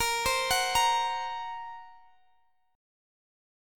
A#sus2#5 Chord